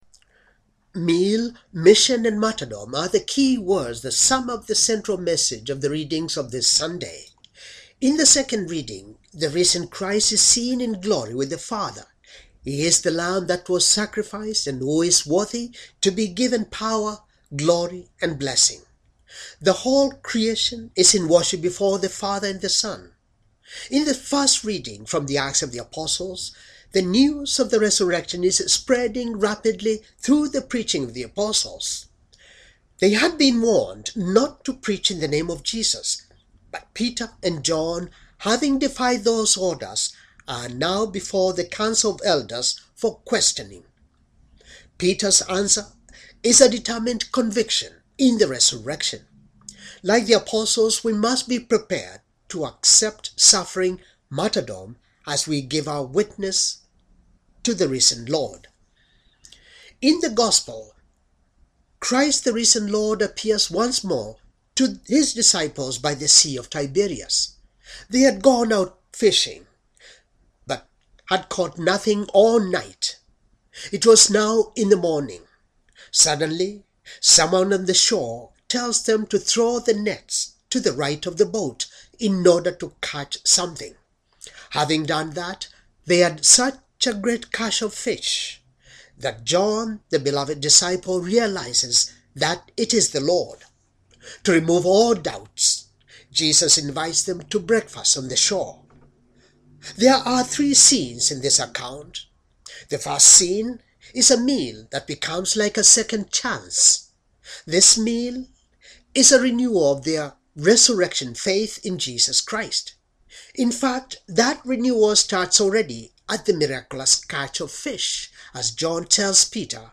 Homily for Third Sunday Easter Year C